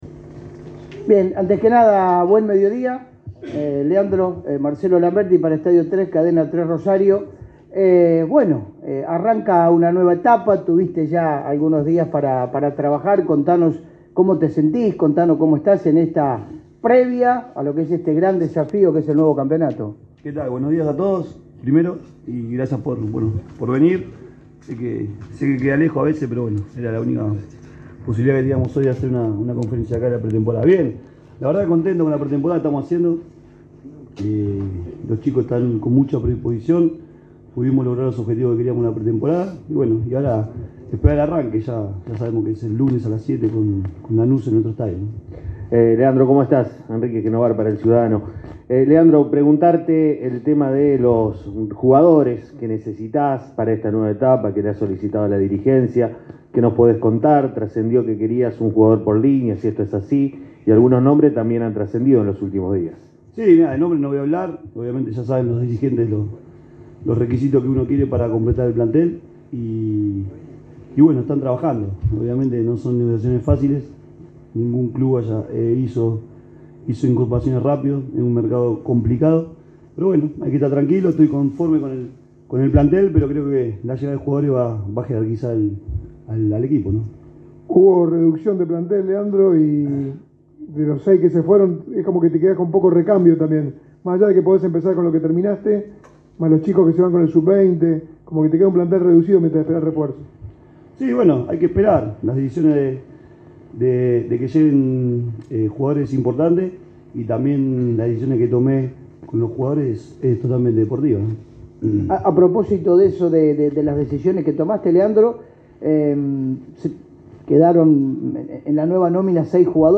En conferencia de prensa, Leandro Somoza contó cómo está trabajando con el grupo, se refirió a la salida de Emiliano Vecchio y enumeró sus objetivos en el club.
somoza-conferencia.mp3